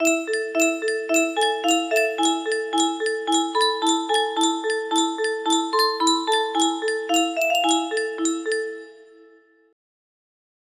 Hava Nagila music box melody